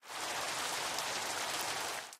rain8.ogg